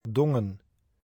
Dongen (Dutch pronunciation: [ˈdɔŋə(n)]